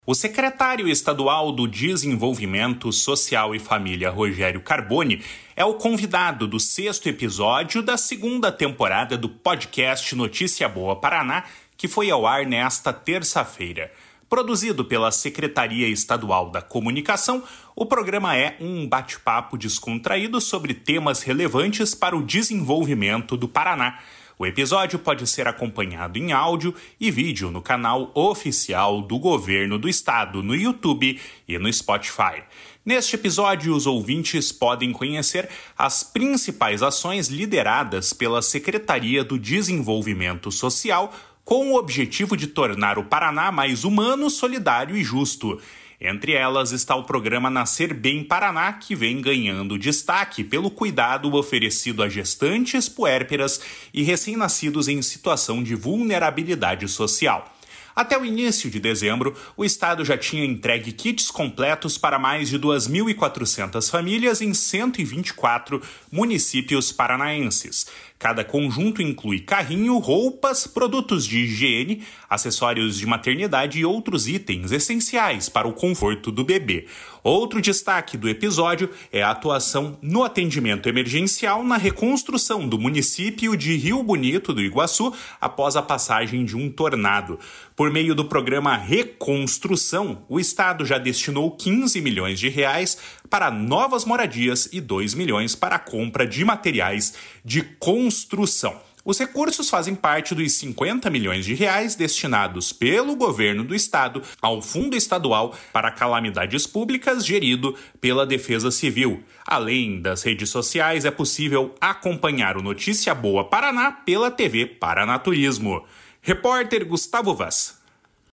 O secretário estadual do Desenvolvimento Social e Família, Rogério Carboni, é o convidado do sexto episódio da segunda temporada do podcast Notícia Boa Paraná, que foi ao ar nesta terça-feira. Produzido pela Secretaria Estadual da Comunicação, o programa é um bate-papo descontraído sobre temas relevantes para o desenvolvimento do Paraná.